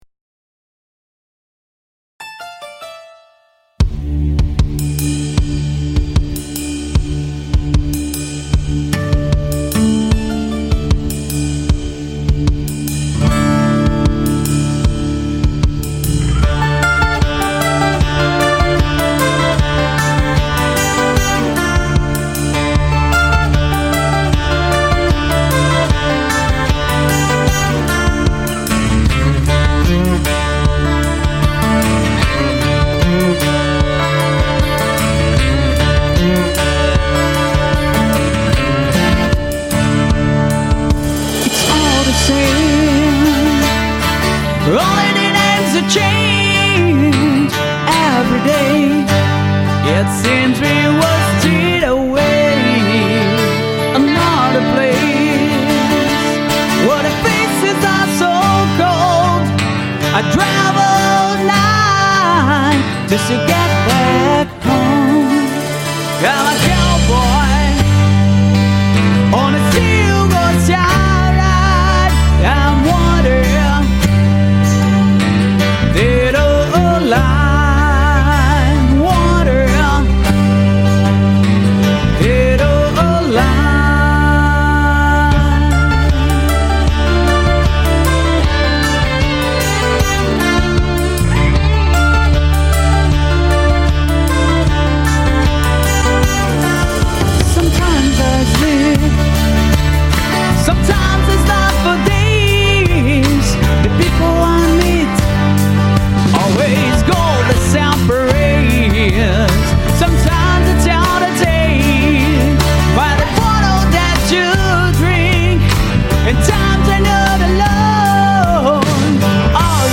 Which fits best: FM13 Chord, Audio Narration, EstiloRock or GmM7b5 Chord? EstiloRock